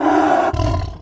Sound / sound / monster / tiger / dead_1.wav